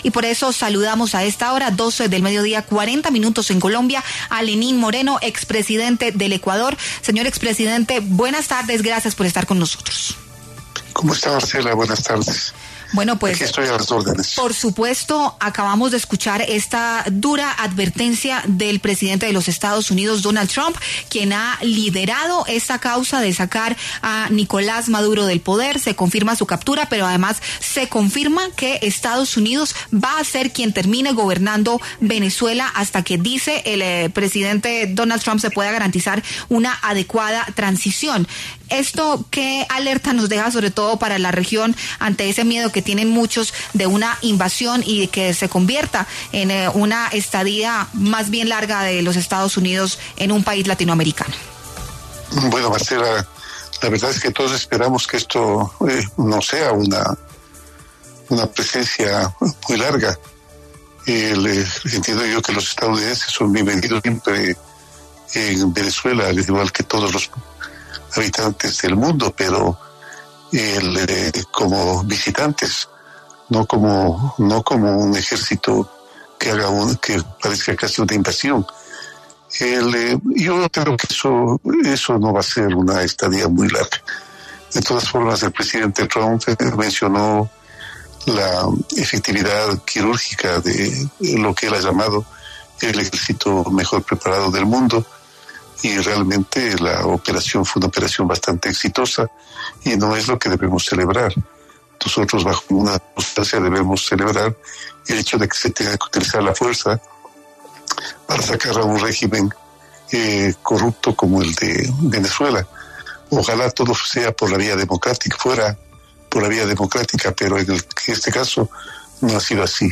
Lenín Moreno, expresidente de Ecuador, conversó con La W luego de la conferencia de prensa de Donald Trump en la que anunció que Estados Unidos gobernará en Venezuela hasta que haya una “transición segura”.